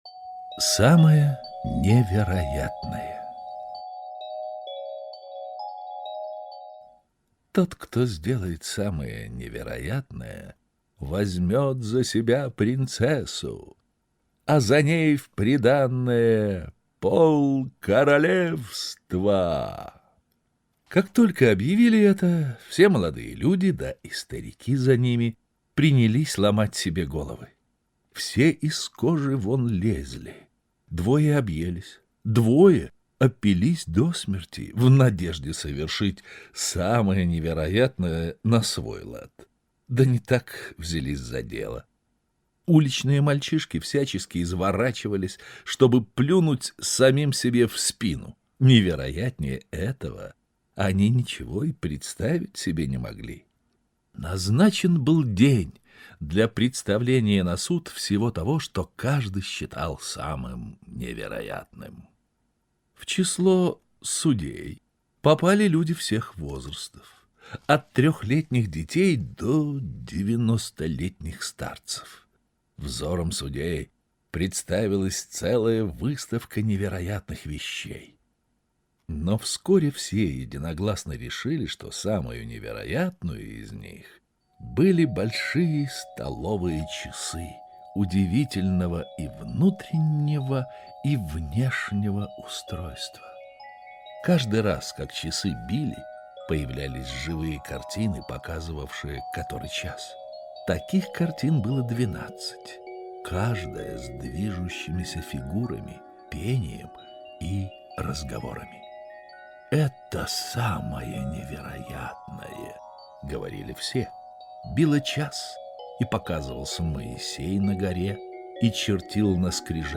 Самое невероятное - аудиосказка Андерсен Г.Х. Король пообещал руку принцессы и полцарства тому, кто сделает что-то самое уникальное на свете.